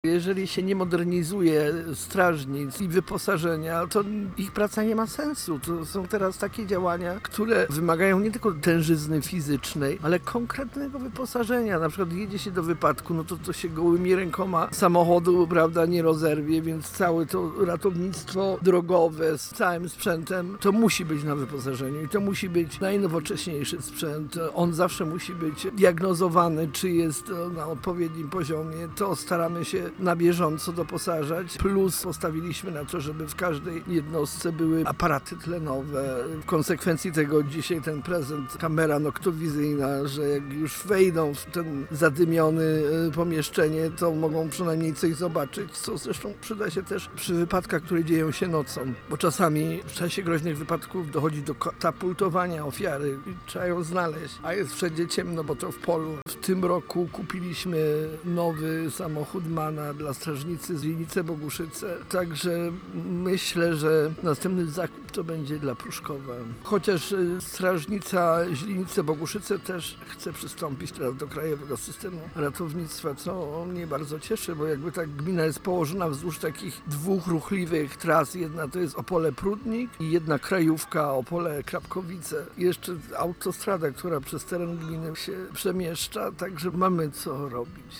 – mówi Róża Malik.
2-roza-malik-burmistrz-proszkowa-140-lecie-instnienia-osp-ochotniczej-strazy-pozarnej.wav